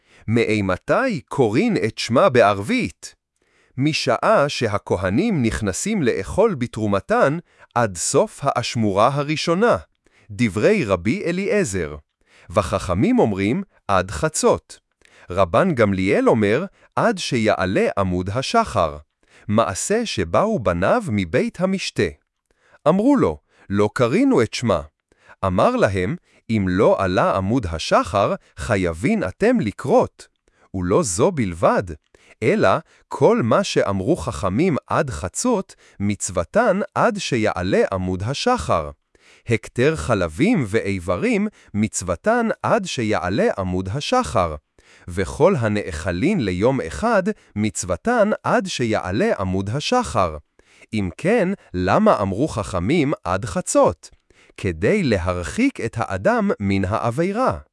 שיתוף | SoundForge AI אתר להפיכת טקסט לדיבור ברמה מקצועית!